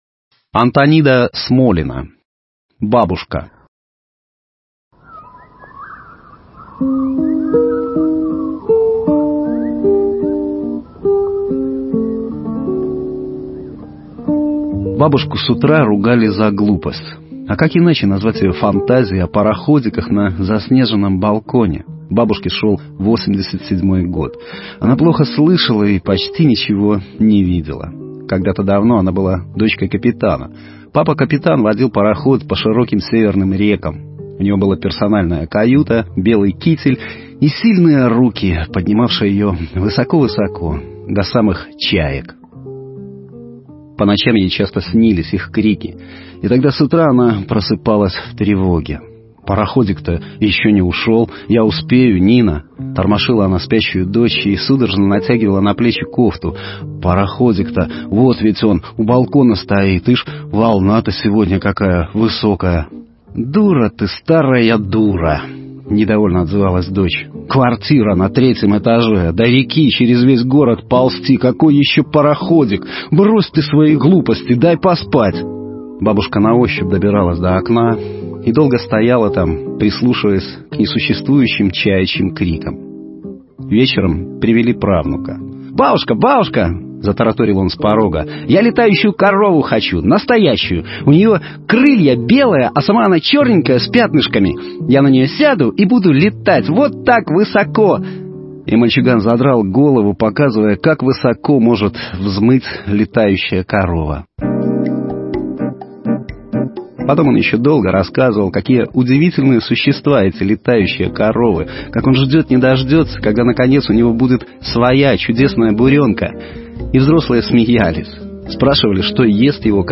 Аудиокниги
Аудио-Рассказы